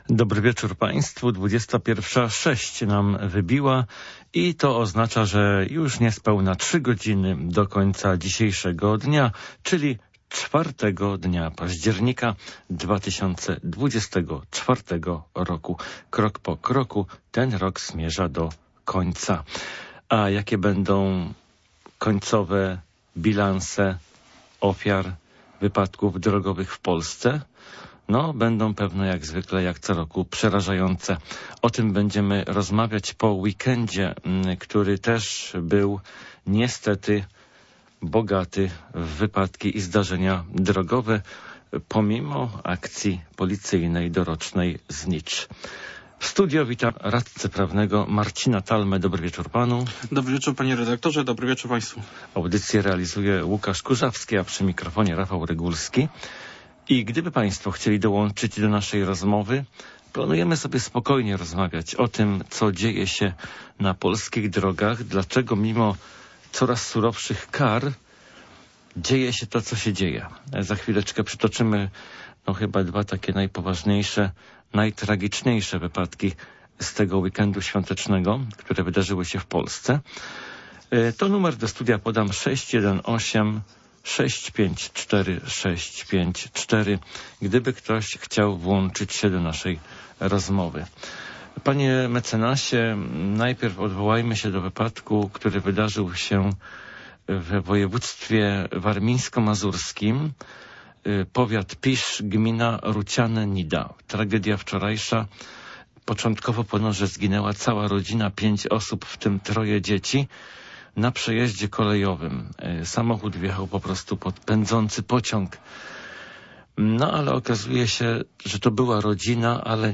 O tym i kilku innych kwestiach rozmawiamy z radcą prawnym